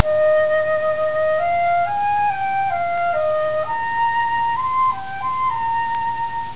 Solution:  You make the files by dividing and then multiplying by 2 (7 bits), dividing and then multiplying by 4 (6 bits), etc., all the way up to dividing and then multiplying by 128, which uses only 1 bit to record each amplitude.
flute7.wav